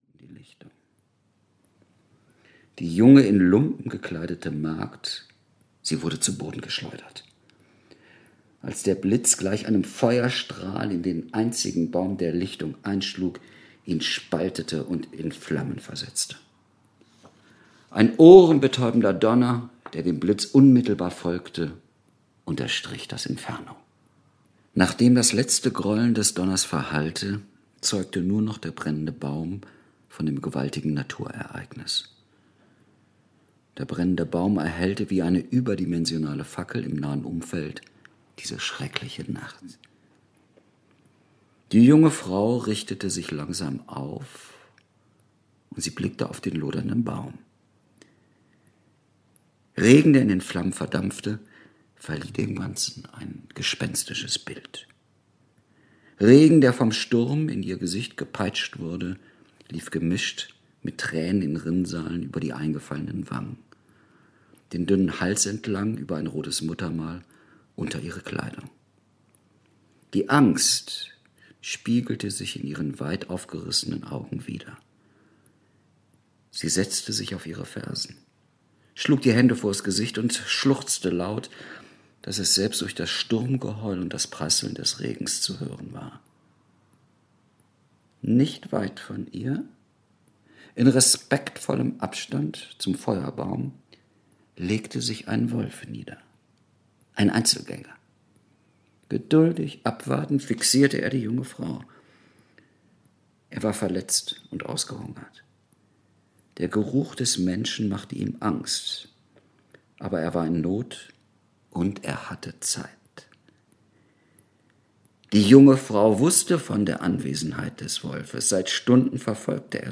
Jakob, der stumme Krieger - Harald Wieczorek - Hörbuch